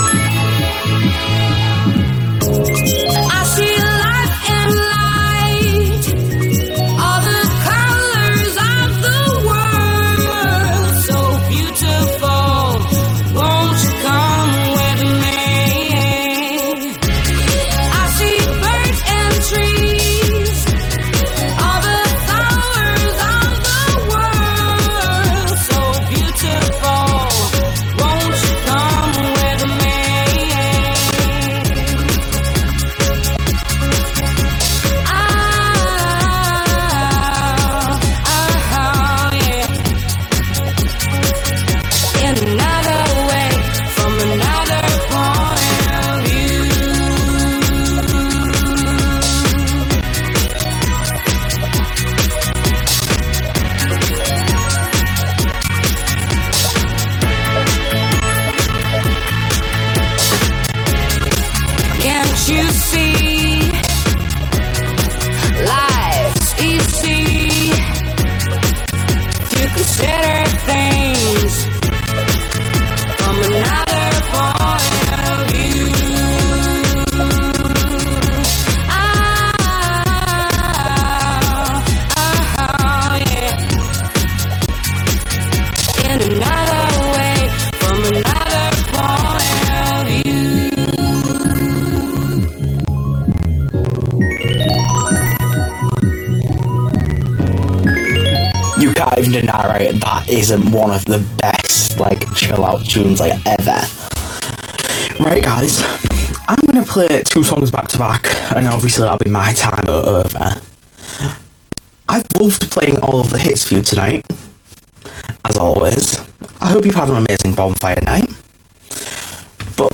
Glitchy audio - is it Windows 11? - Stereo Tool
Been running ST for a number of years, however over the past couple of months we have started to have audio problems with the garbled audio, or audio that seems to slow up or down (like wow and flutter).